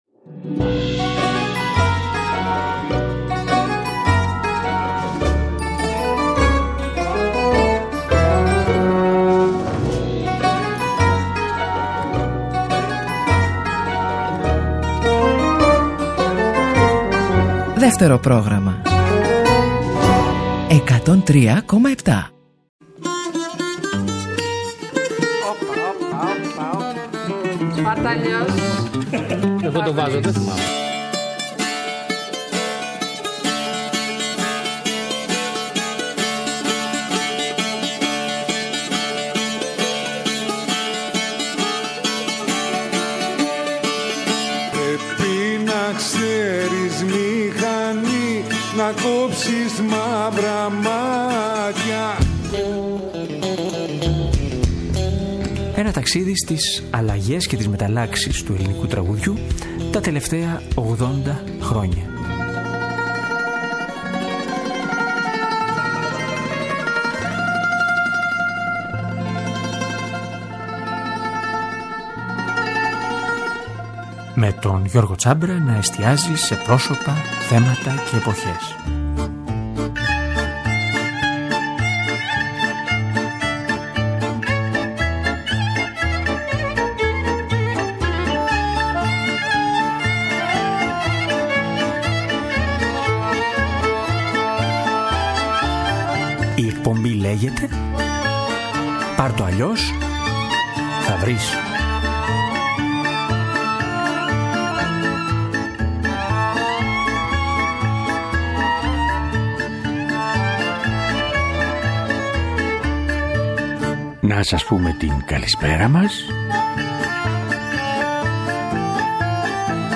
Η σκέψη για την εκπομπή γεννήθηκε με τη διαπίστωση ότι αυτές τις μέρες συμπληρώθηκαν 50 χρόνια από την ηχογράφηση της γνωστής πελοποννησιακής πατινάδας από τον Σίμωνα Καρρά.